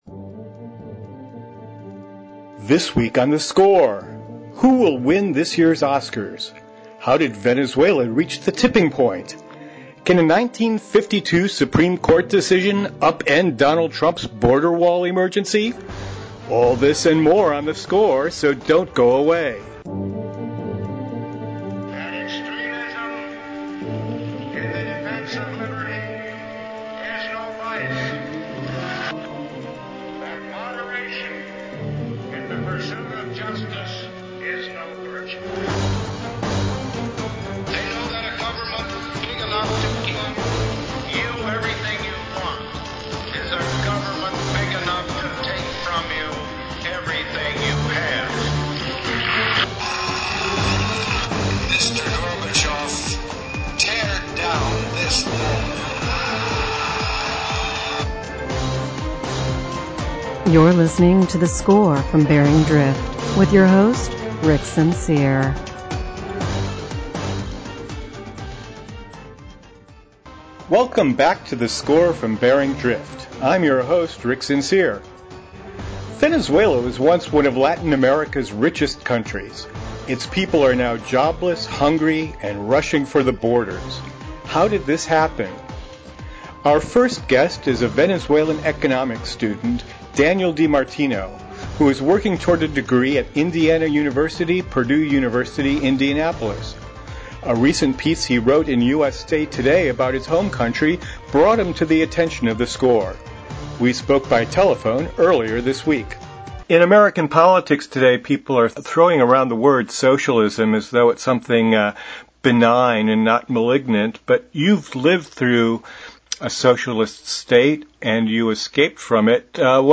We spoke by telephone earlier this week.